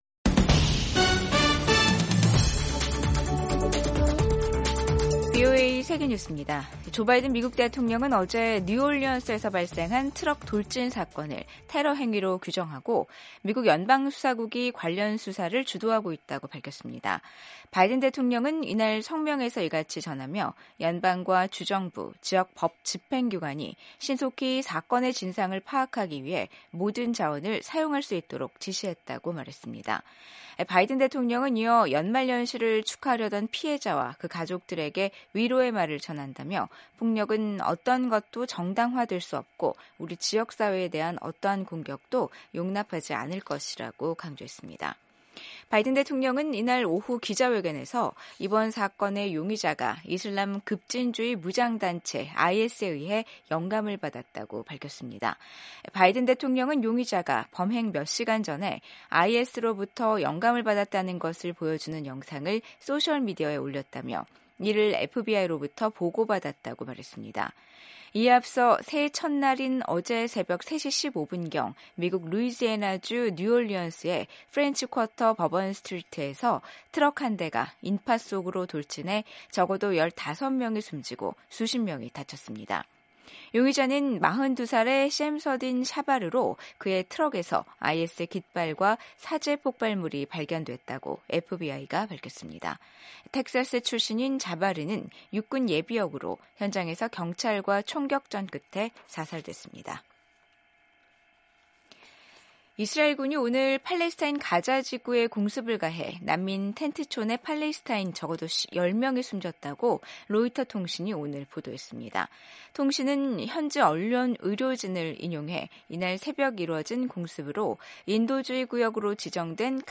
생방송 여기는 워싱턴입니다 2025/1/2 저녁